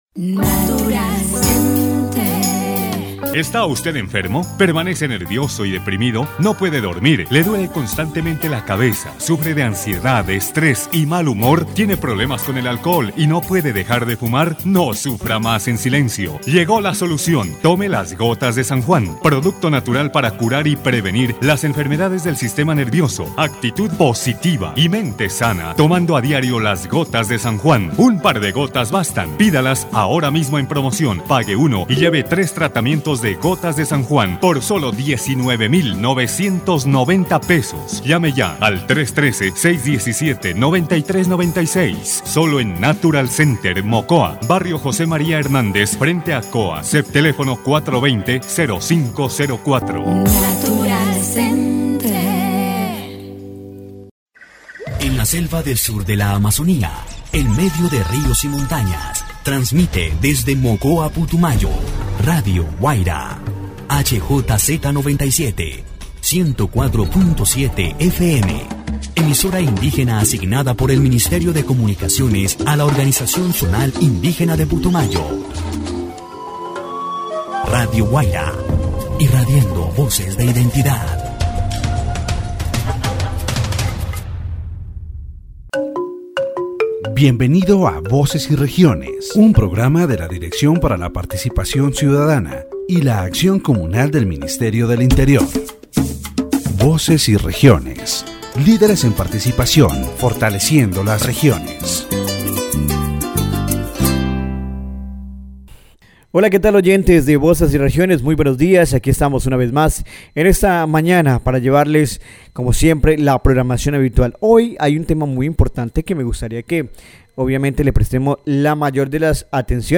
The radio program "Voces y Regiones" on Radio Guaida 104.7 FM, run by the Ministry of the Interior, addresses the issue of architectural barriers and their impact on people with disabilities.